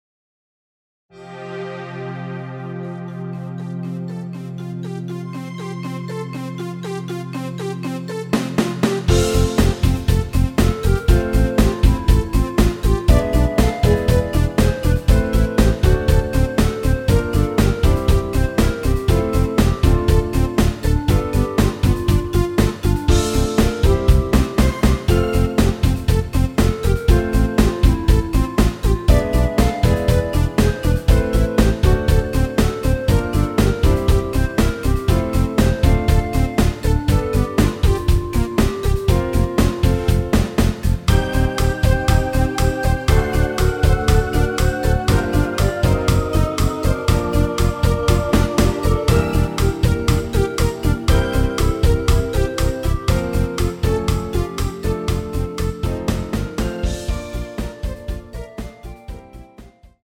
원키에서(-2)내린 멜로디 포함된 MR 입니다.(미리듣기 참조)
엔딩이 페이드 아웃이라 가사의 마지막 까지후 엔딩을 만들어 놓았습니다.!
앞부분30초, 뒷부분30초씩 편집해서 올려 드리고 있습니다.
중간에 음이 끈어지고 다시 나오는 이유는